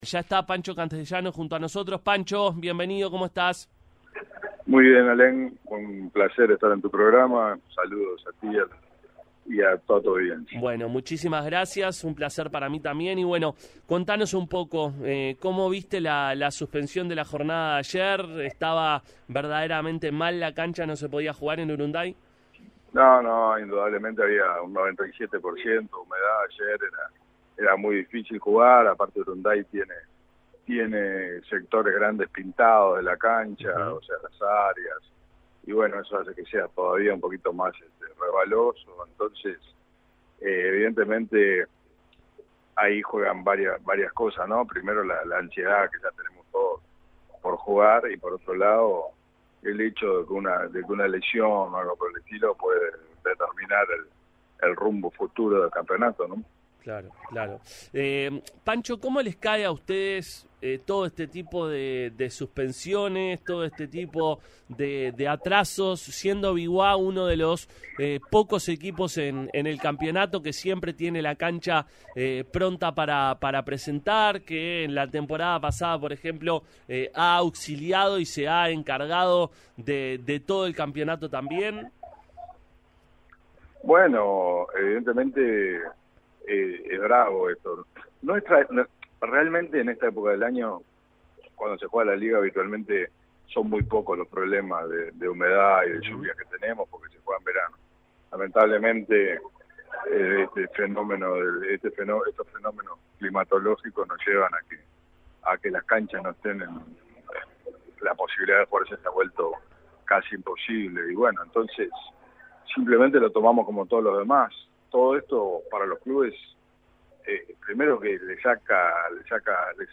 pasó por los micrófonos de Pica la Naranja para hablar de la actualidad de la Liga Uruguaya.